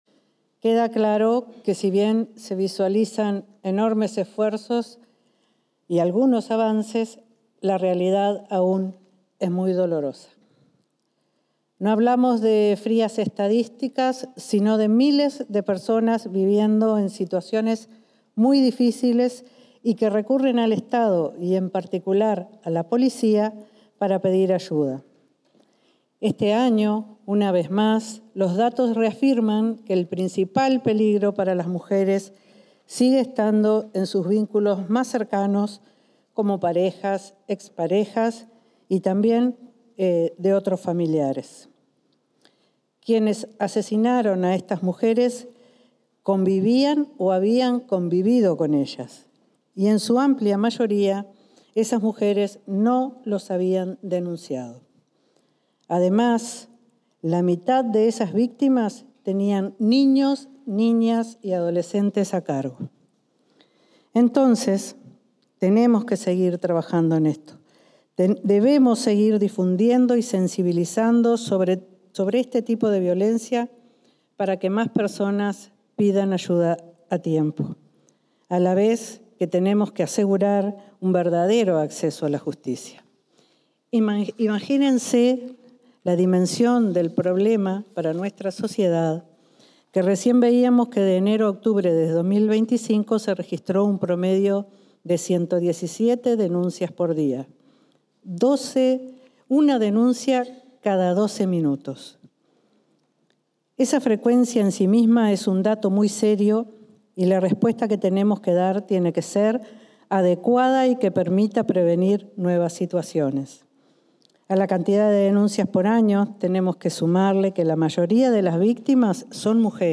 Palabras de autoridades del Ministerio del Interior
Palabras de autoridades del Ministerio del Interior 21/11/2025 Compartir Facebook X Copiar enlace WhatsApp LinkedIn El ministro Carlos Negro, la subsecretaria, Gabriela Valverde, y la directora de Género, July Zabaleta, se expresaron en la presentación de una rendición de cuentas realizada por la cartera sobre violencia basada en género.